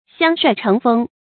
相帥成風 注音： ㄒㄧㄤ ㄕㄨㄞˋ ㄔㄥˊ ㄈㄥ 讀音讀法： 意思解釋： 層層影響成為風氣。